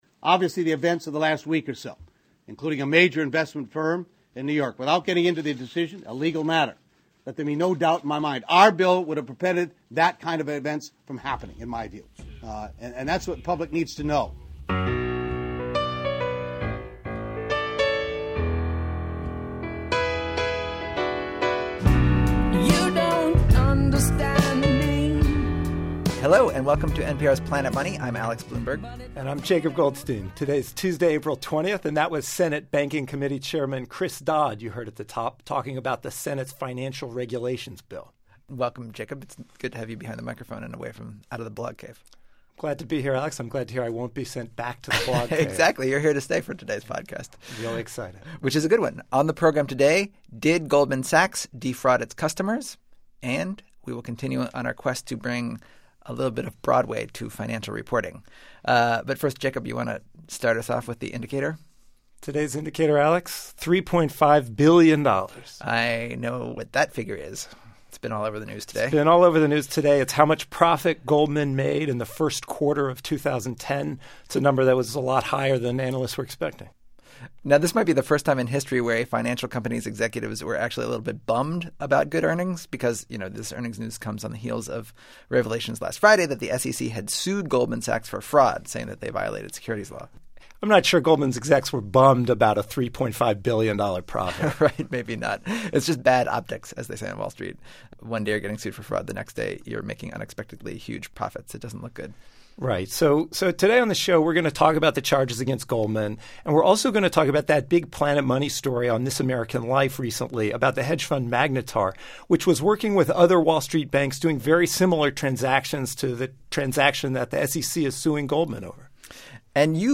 (Spoiler: It's a dramatic reading of a Goldman executive's convoluted answer to a key question about the case.)